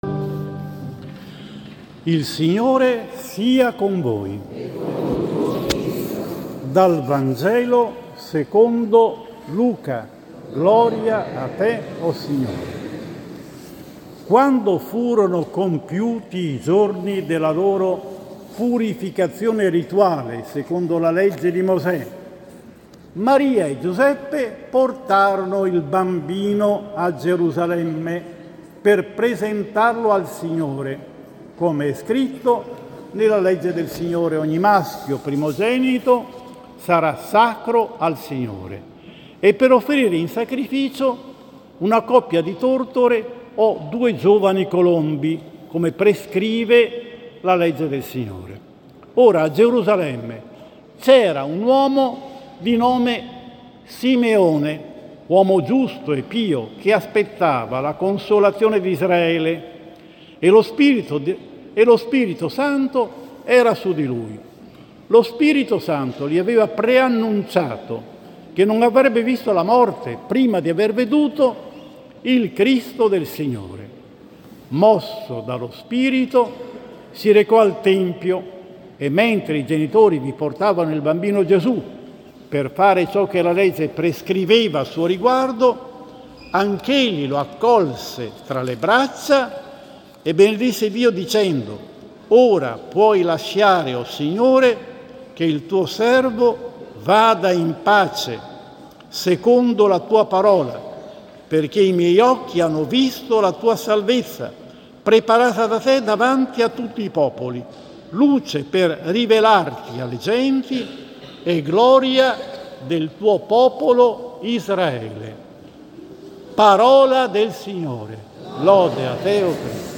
11 Febbraio 2024, VI.a DOMENICA, Tempo ordinario, anno B: omelia